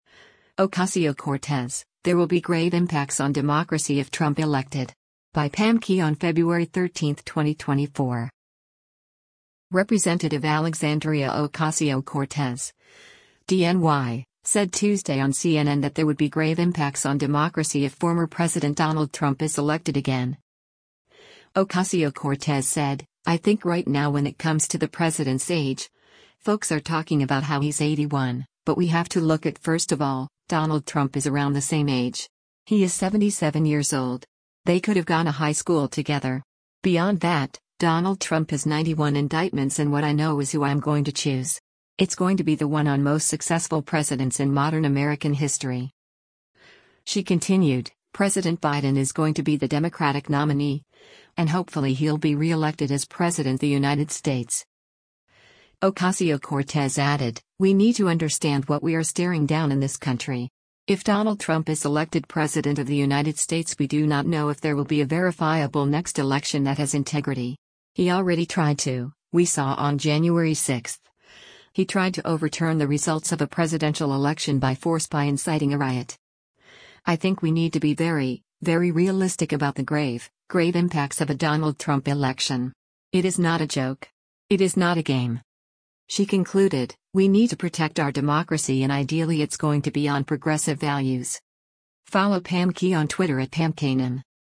Representative Alexandria Ocasio-Cortez (D-NY) said Tuesday on CNN that there would be “grave” impacts on democracy if former President Donald Trump is elected again.